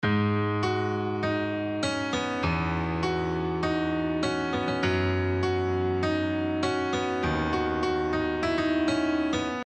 Ich spiele einen Schnipsel mit Sustain ab, verschiebe ihn und das Sustain wird nicht mehr benutzt.
Und hier jeweils ein Export von Position 1 (Sustain funktioniert) und Position 2 (Sustain funktioniert nicht mehr):